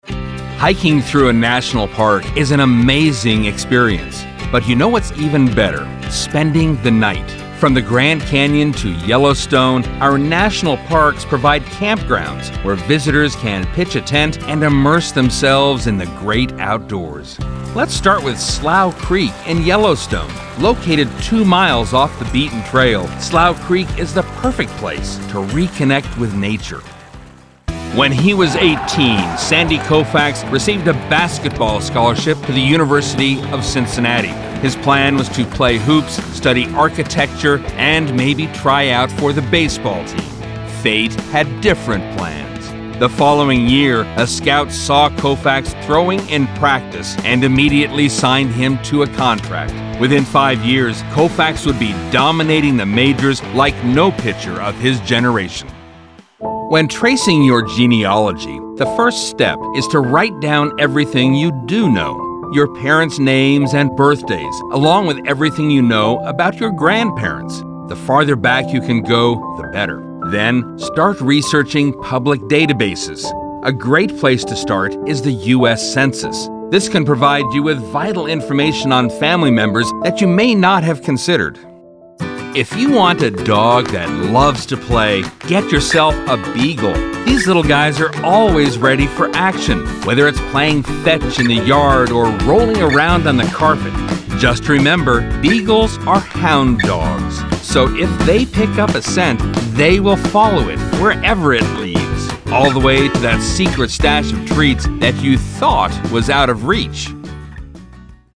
Male
Adult (30-50), Older Sound (50+)
Explainer Videos
Variety Of Narration Samples